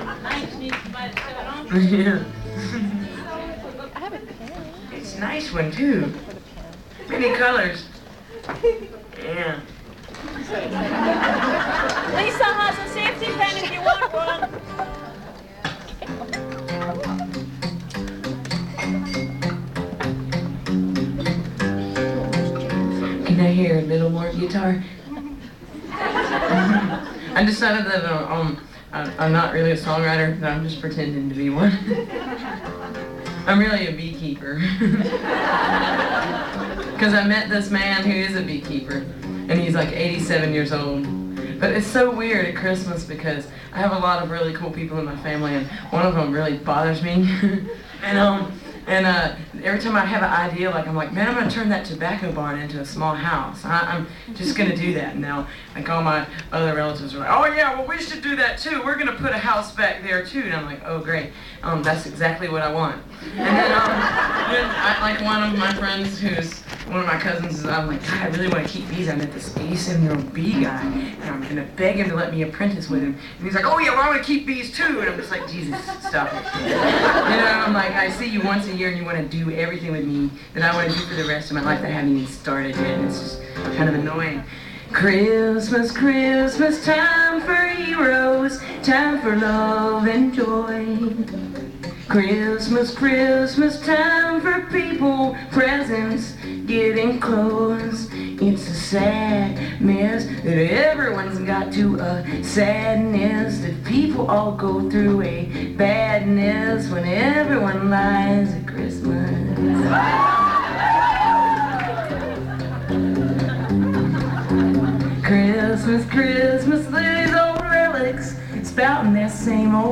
(songwriters in the round)